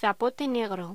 Locución: Zapote negro
voz